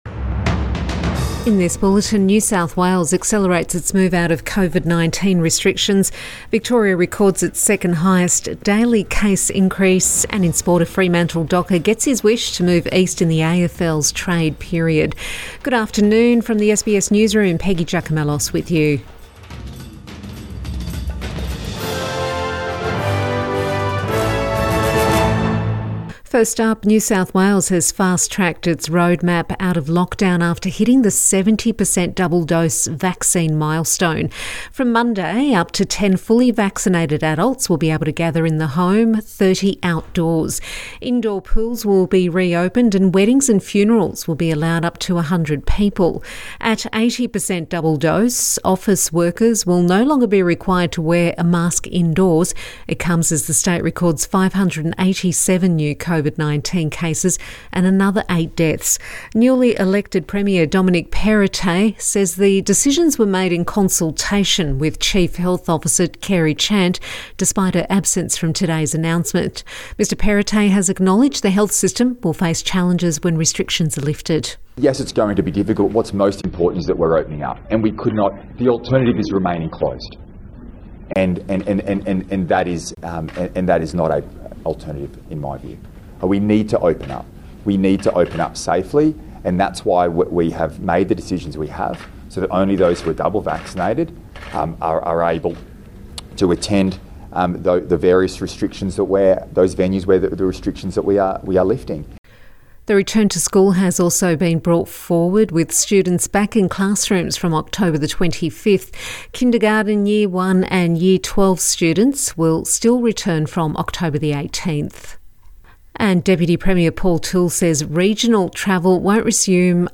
PM bulletin October 7 2021